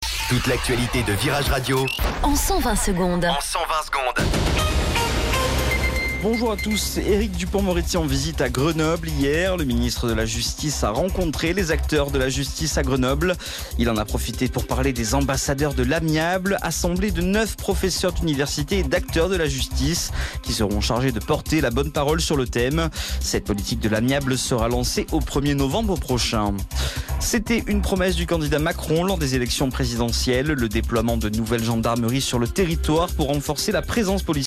Flash Info Grenoble